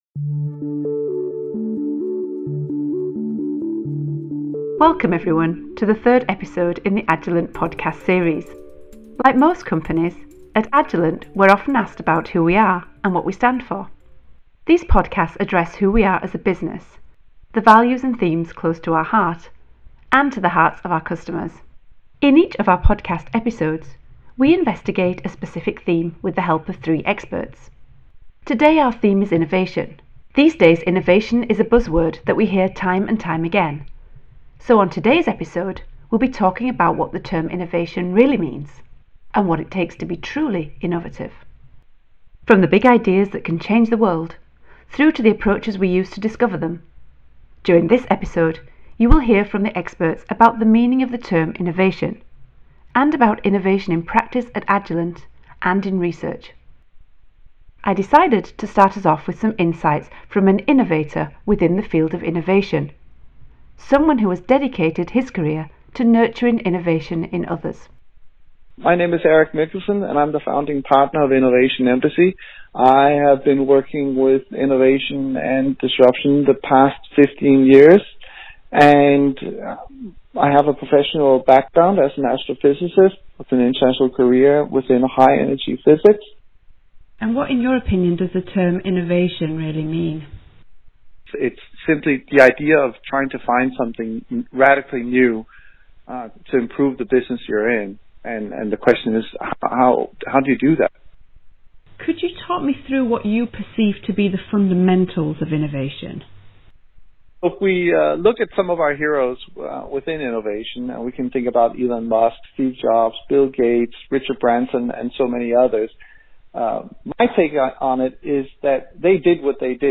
From the ‘big ideas’ that can change the world, through to the approaches we use to discover them, join Agilent in this podcast as we explore the meaning of the word innovation, and the significance of innovation in practice. Hear from the experts, entrepreneurs and business leaders as they discuss the meaning of innovation, its challenges, and what innovation in practice looks like.